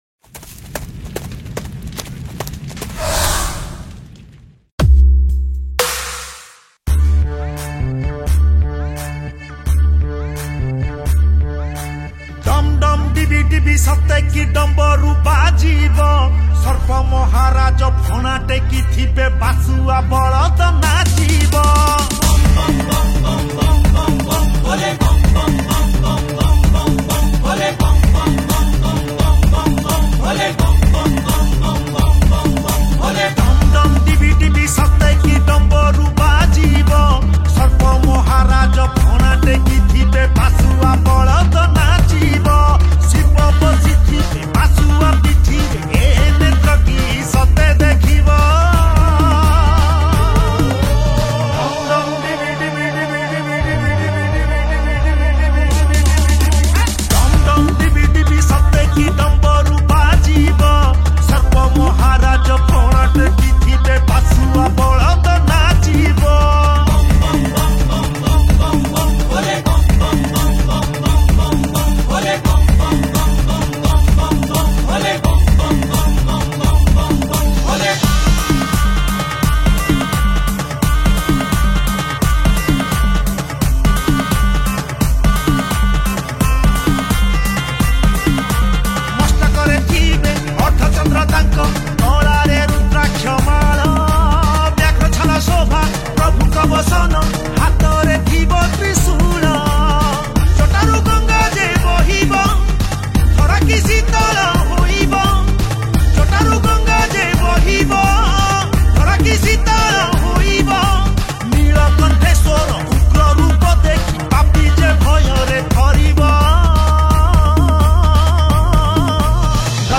Bolbum Special Song Songs Download
Chorus